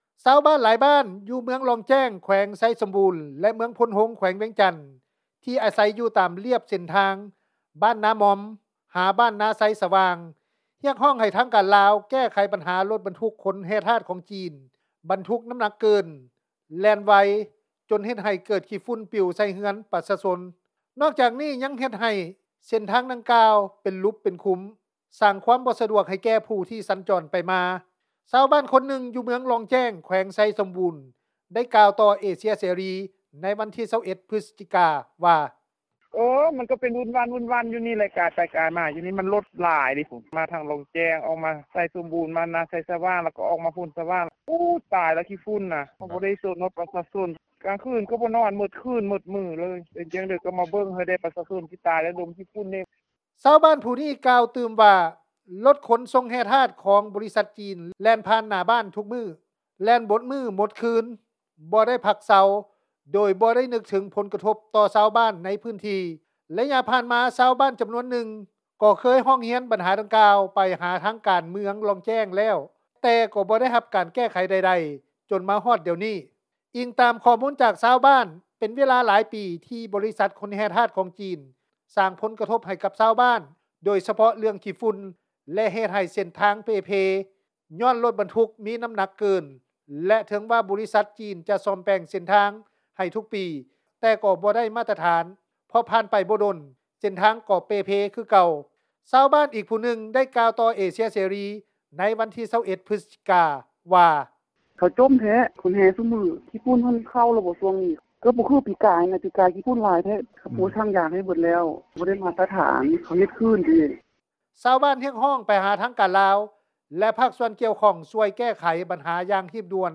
ຊາວບ້ານຄົນນຶ່ງ ຢູ່ເມືອງລ່ອງແຈ້ງ ແຂວງ ໄຊສົມບຸນ ກ່າວຕໍ່ວິທຍຸ ເອເຊັຽເສຣີ ໃນວັນທີ 21 ພຶສຈິກາ ວ່າ:
ຊາວບ້ານອີກຜູ້ນຶ່ງ ໄດ້ກ່າວຕໍ່ວິທຍຸ ເອເຊັຽເສຣີ ໃນວັນທີ 21 ພຶສຈິກາ ວ່າ:
ຊາວບ້ານອີກຄົນນຶ່ງ ໄດ້ກ່າວຕໍ່ວິທຍຸ ເອເຊັຽເສຣີ ວ່າ: